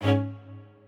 strings6_26.ogg